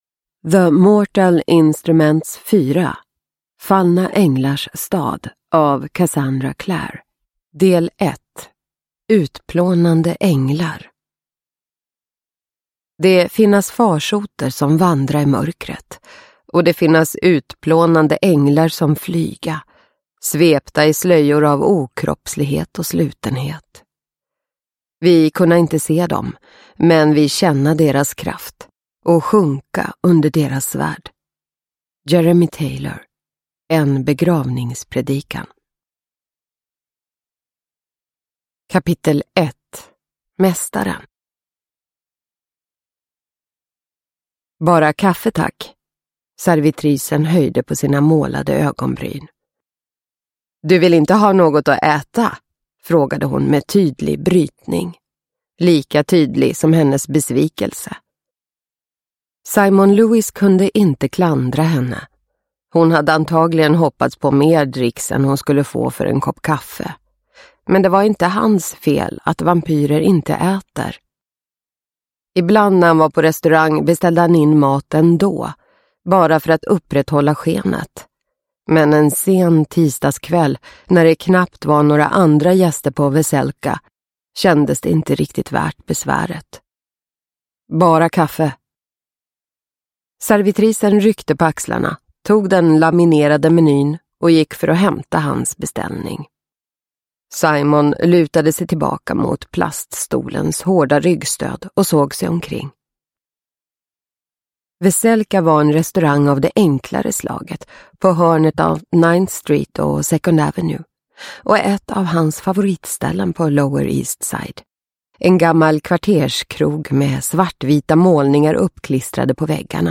Fallna änglars stad – Ljudbok – Laddas ner